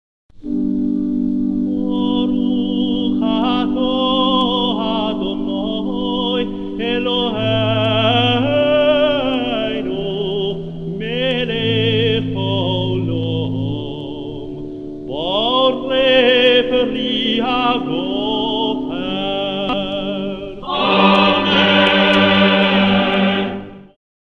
1ère bénédiction (rite ashkenaze
accompagné à l’orgue